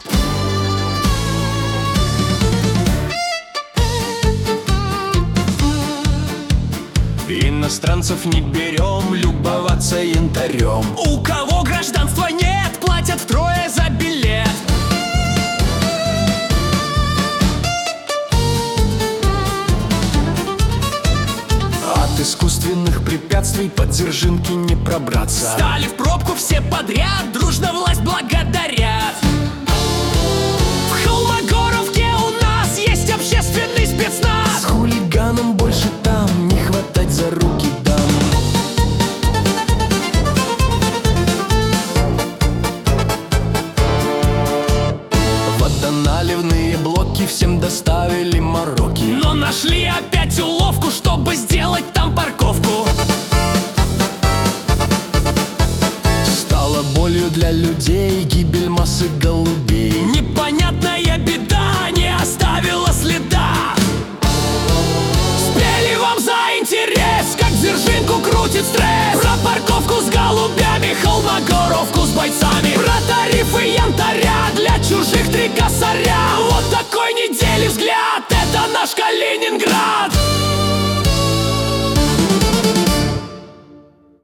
Музыкальный дайджест калининградских новостей за неделю (видео)
Куплеты на злобу дня о главных и важных событиях